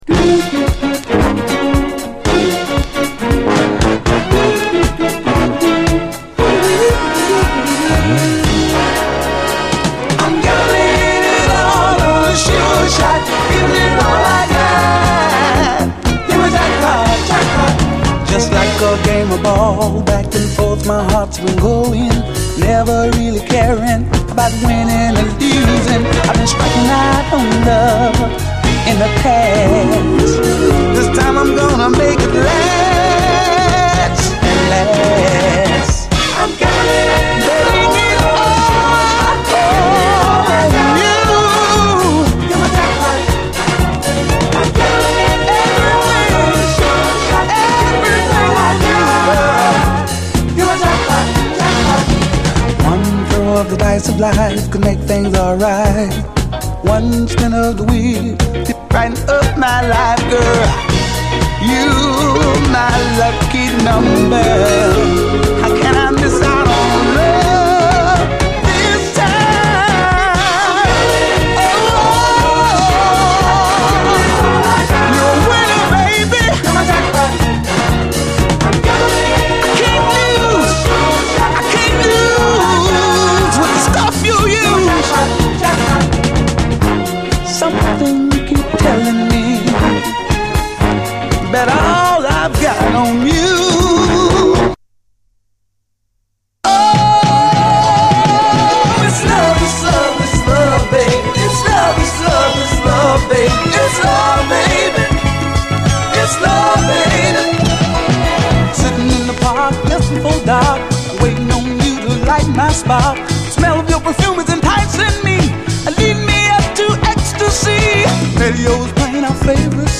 ピュインピュインと伸びやかなシンセがめちゃくちゃ気持ちいい！